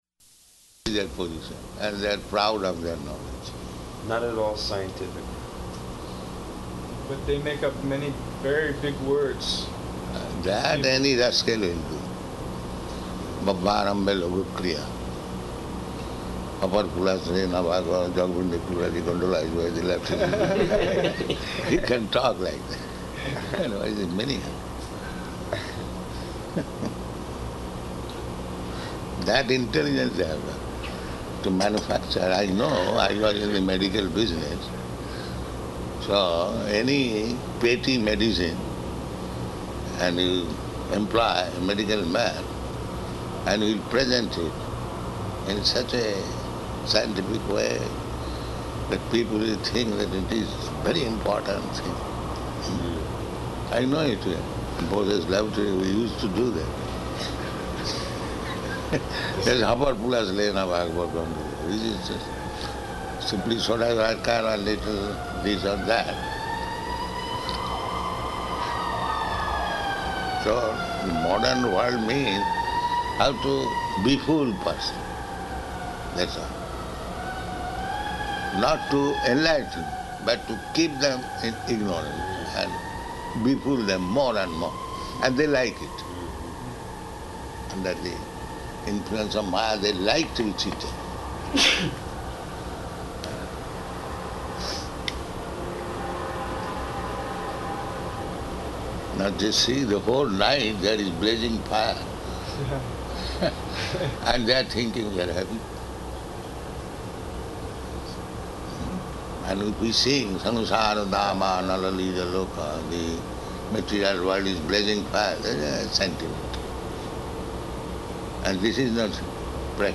Morning Walk --:-- --:-- Type: Walk Dated: July 12th 1976 Location: New York Audio file: 760712MW.NY.mp3 [in room] Prabhupāda: This is their position, and they are proud of their knowledge.
[sounds of fire engines] Now just see, the whole night there is blazing fire, and they are thinking they are happy.